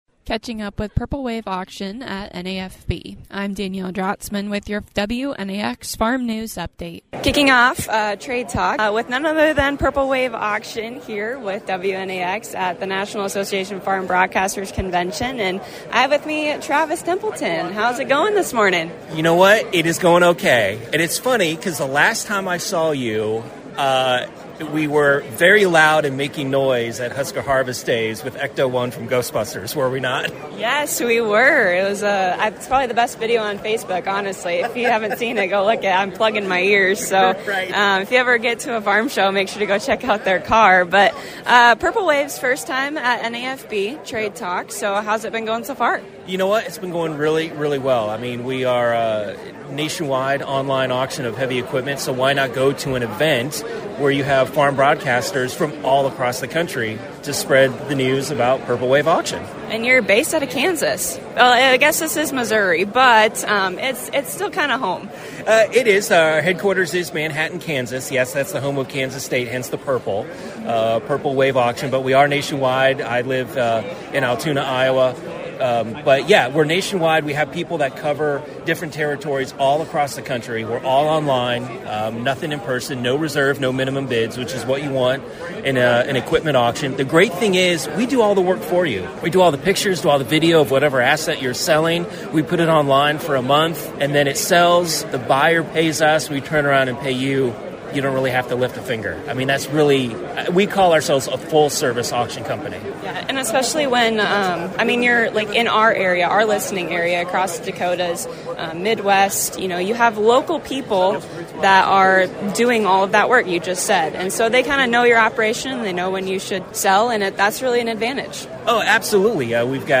Catching up with Purple Wave Auction at 2025 National Association Farm Broadcasters Convention.